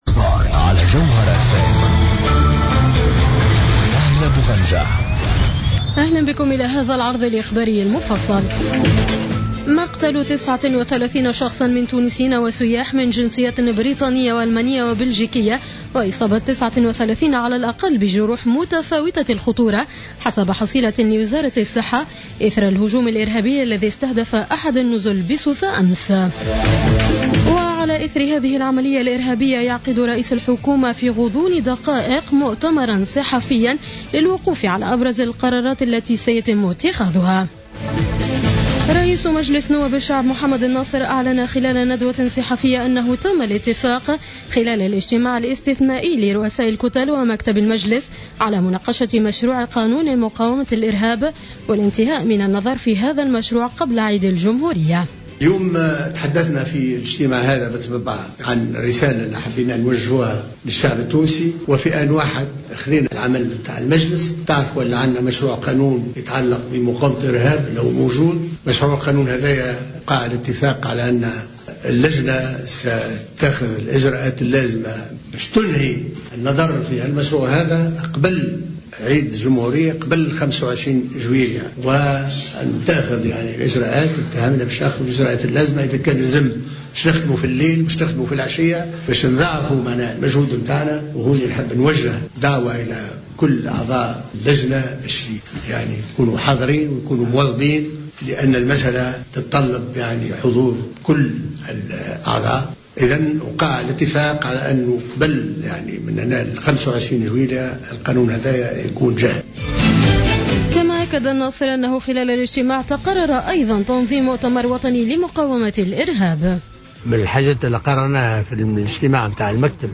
نشرة أخبار منتصف الليل ليوم السبت 27 جوان 2015